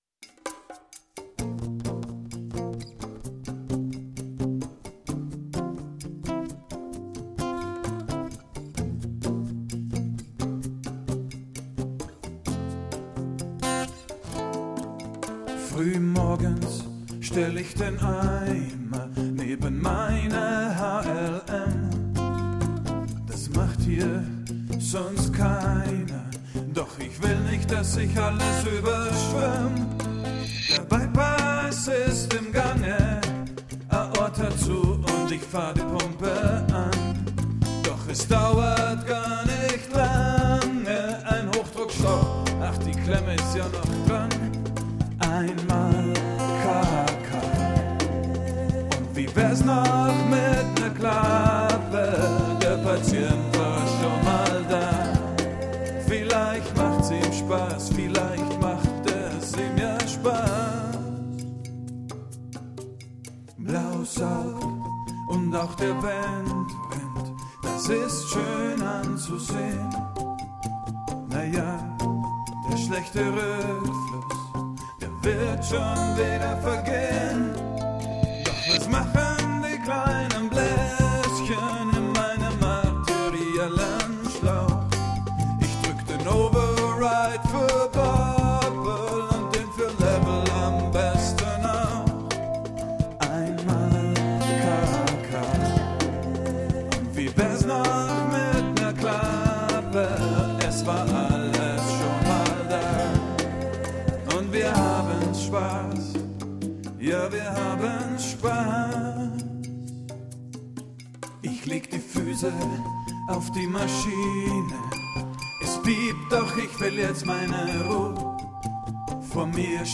Der erste Kardiotechnikersong der Welt: